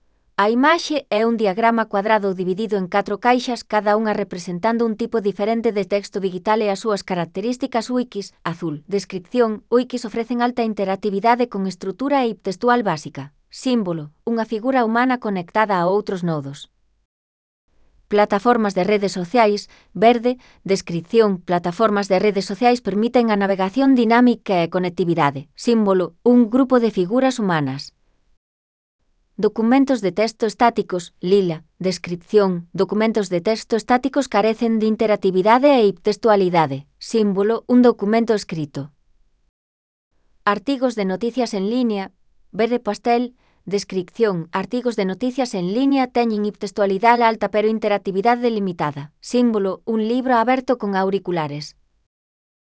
Audio con la descripción de la imagen